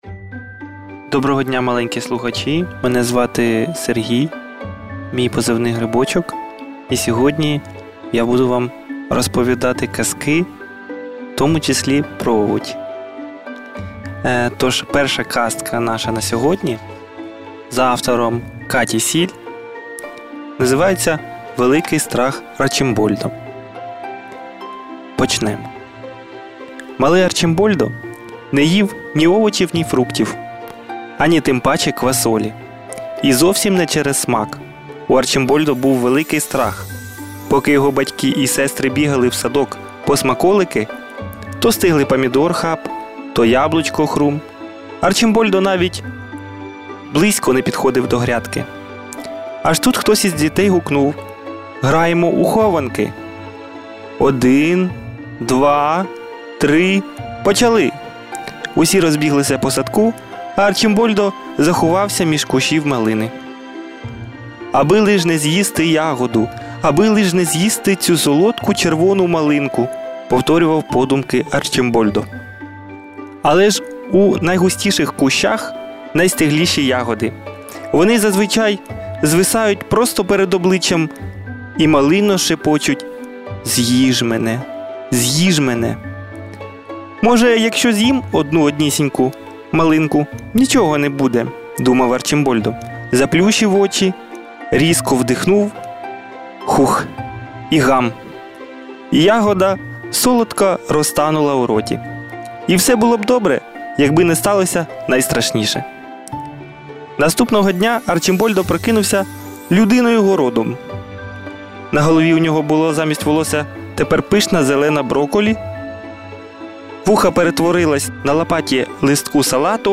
Історії від сучасних українських авторів зачитають батьки, які попри будь-яку відстань завжди поруч.